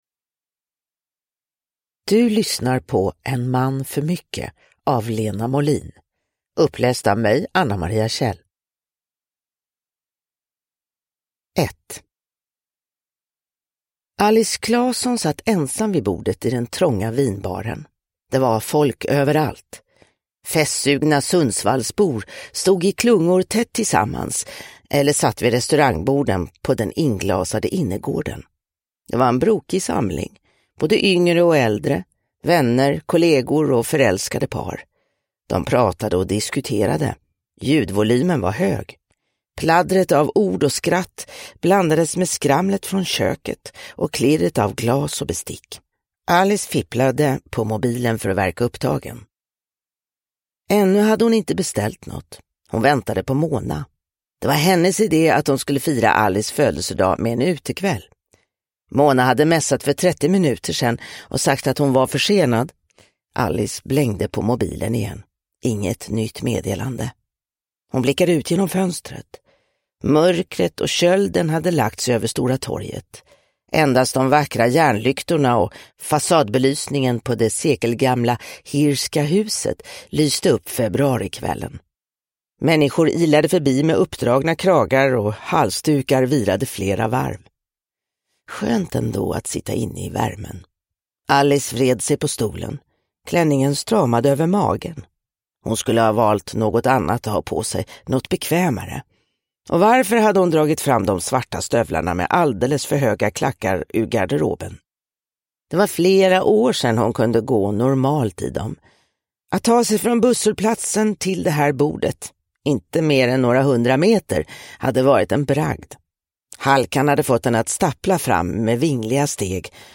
En man för mycket / Ljudbok